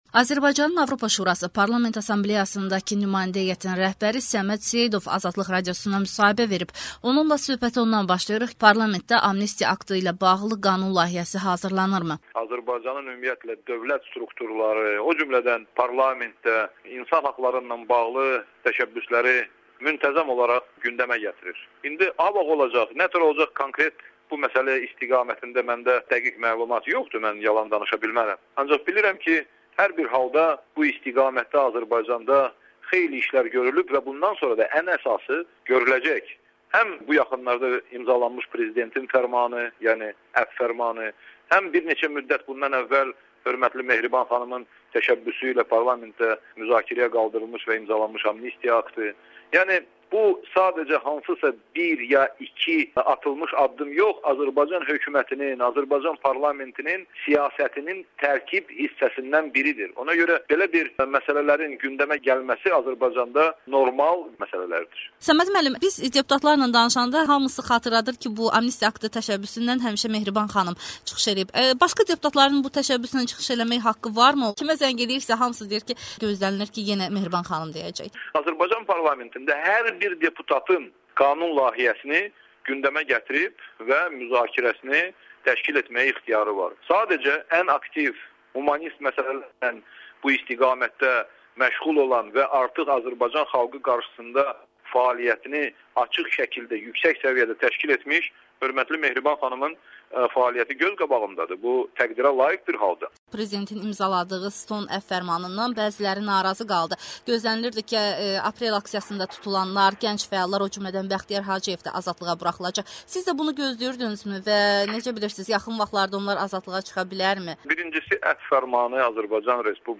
Azərbaycanın AŞPA-dakı nümayəndə heyətinin rəhbəri Səməd Seyidov AzadlıqRadiosuna müsahibə verib